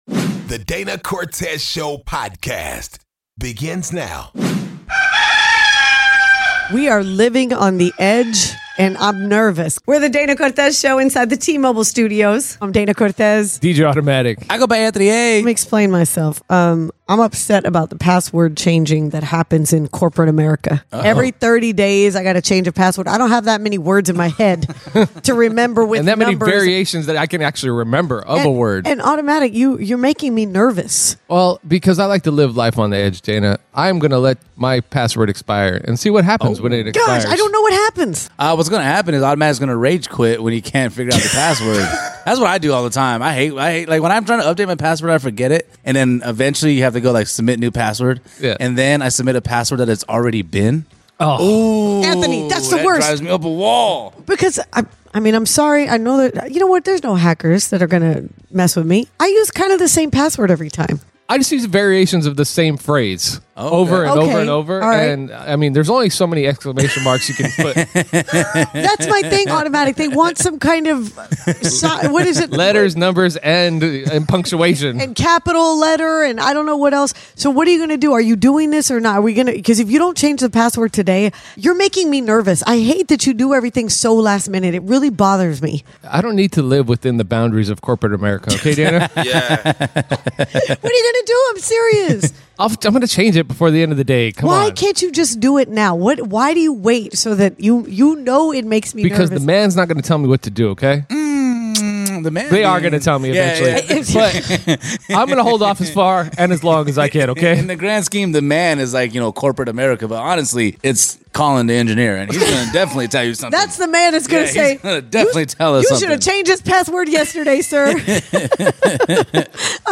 DCS talks spanking kids and the pros and cons of it. The callers on the topic felt very different than DCS.